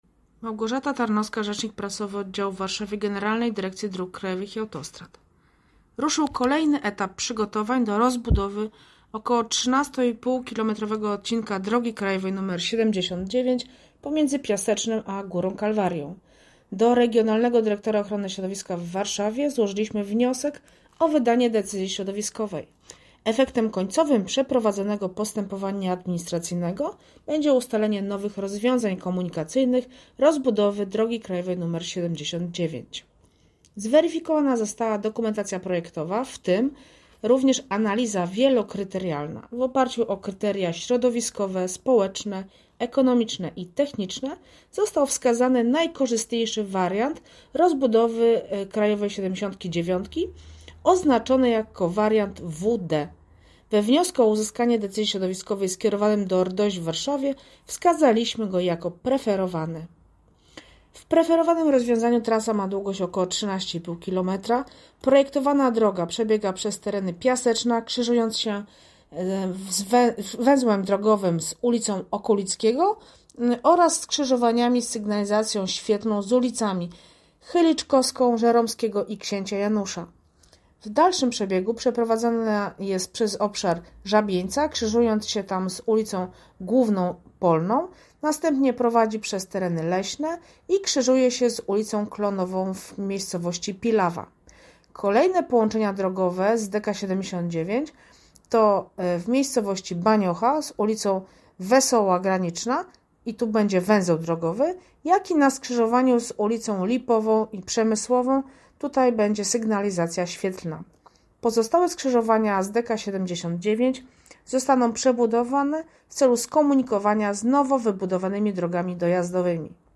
Złożyliśmy_wniosek_o_wydanie_decyzji_środowiskowej_dla_rozbudowy_DK79_-_wypowiedź_rzecznika